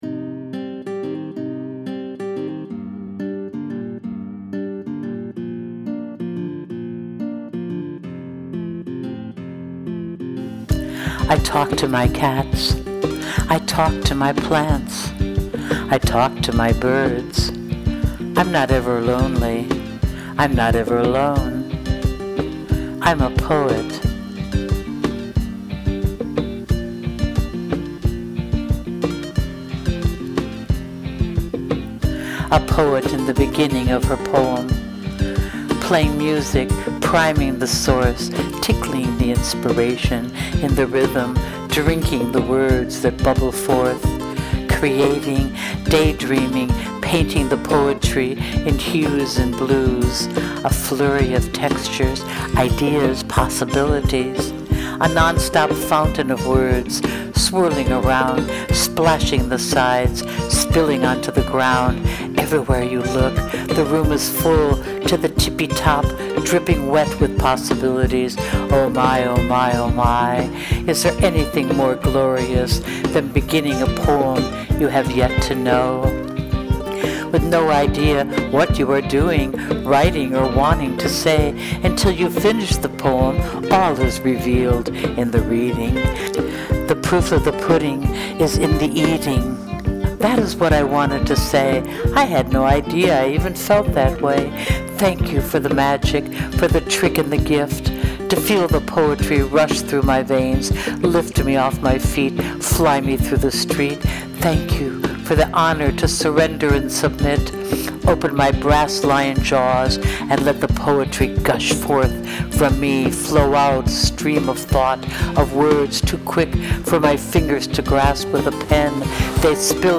It was my music (composed on Garage Band) and one of the pieces I most loved and I sent them many proofs…in the end I lost my fight 😦
So..here it is on my blog, where no one can mess with me:) It is the background to a word flow that the music inspired, Please listen to it (just click Play) and enjoy!!!